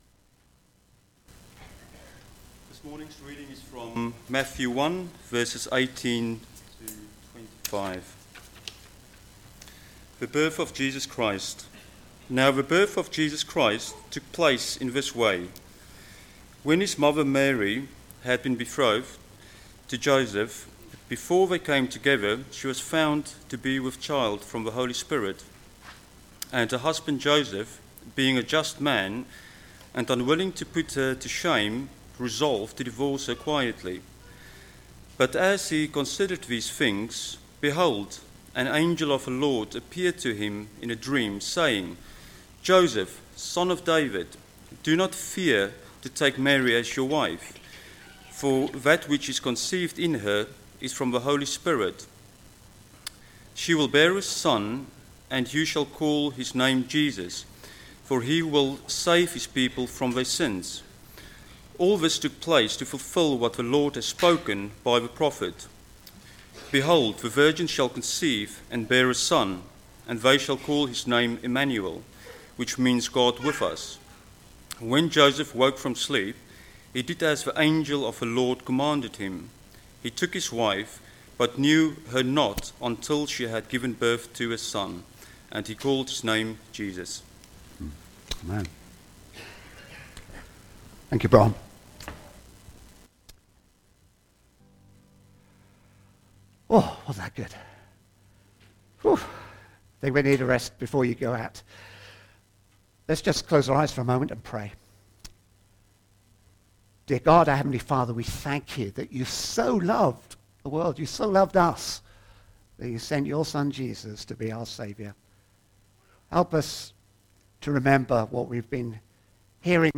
Family Carol Service